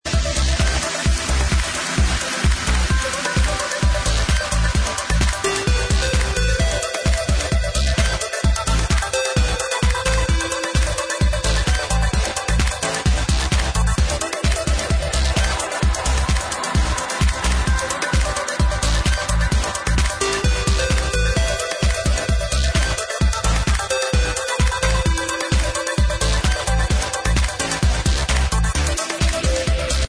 nice music for afterparty